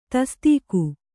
♪ tastīku